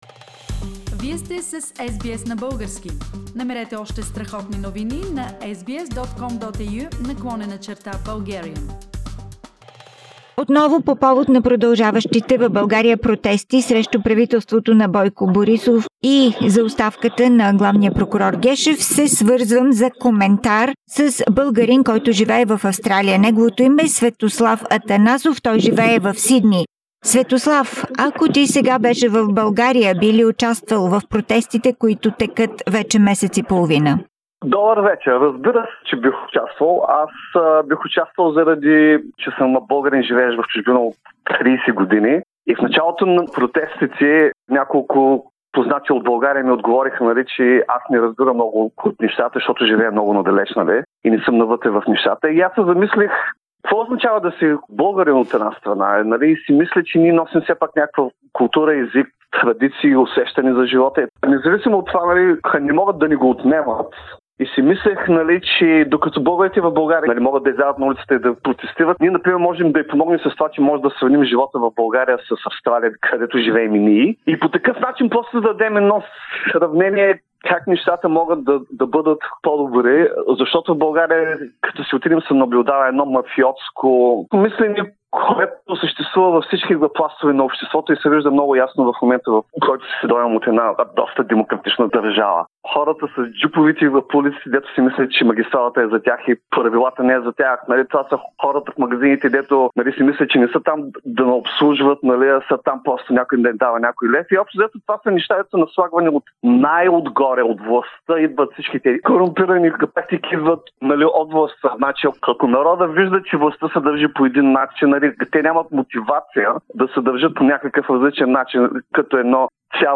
Българи от Австралия за анти-правителствените протести